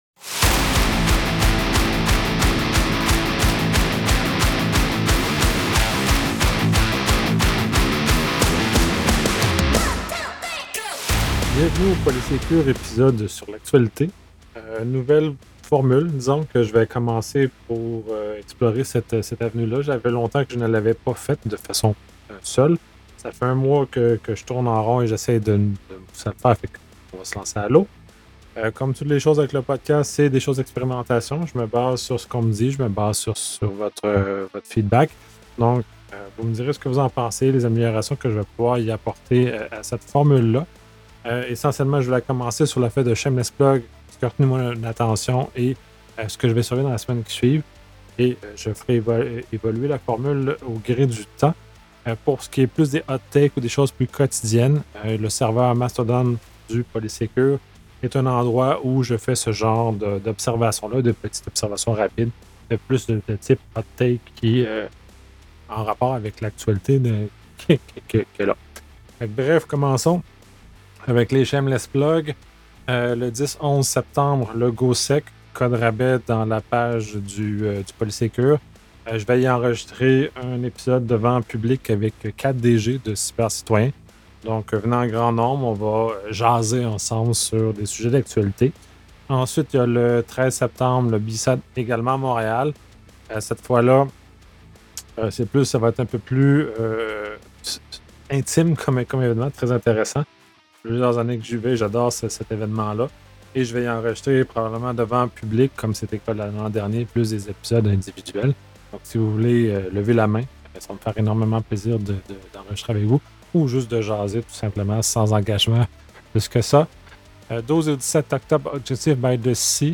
Préambule Bon… je saute à l’eau et je repars un podcast sur l’actualité en mode seul.
Aussi, et probablement le plus audible, j’ai eu un glitch à l’enregistrement.